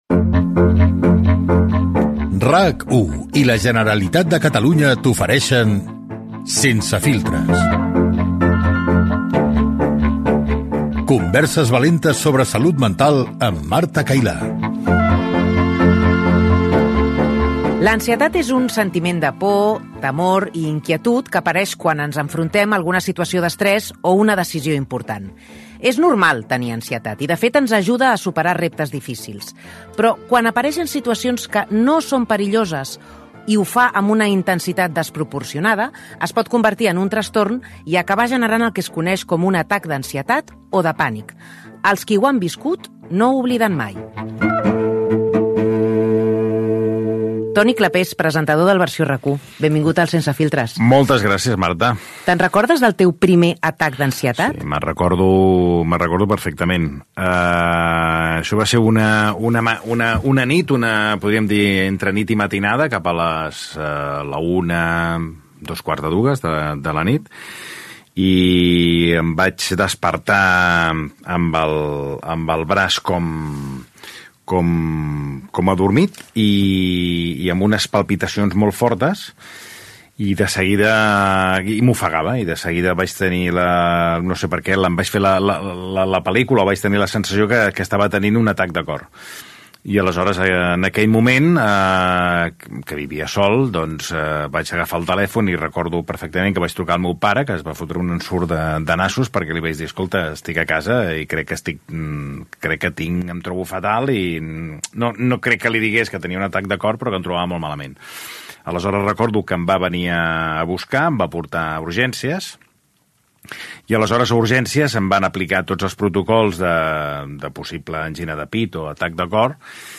Careta del programa, espai dedicat a l'ansietat amb una entrevista al presentador Toni Clapés